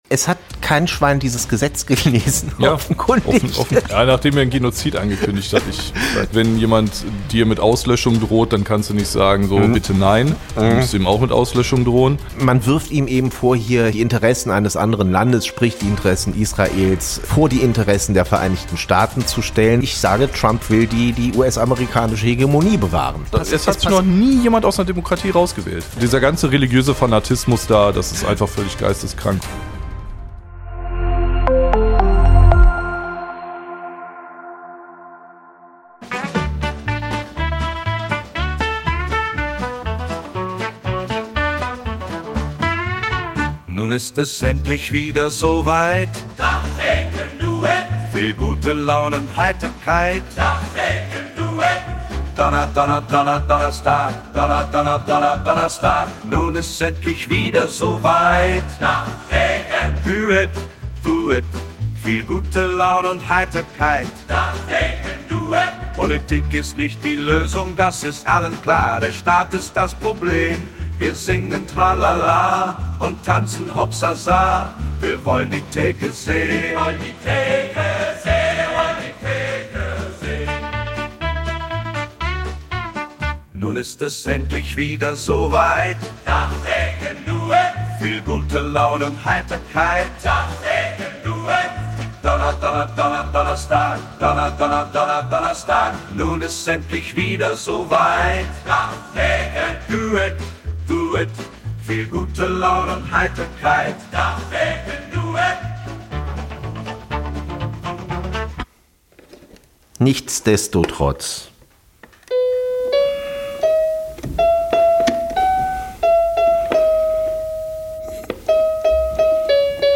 Kleine Redaktionskonferenz am Tresen
Wie immer werden in dieser Reihe an der ef-Theke spontan aktuelle Entwicklungen und brennende Themen beleuchtet.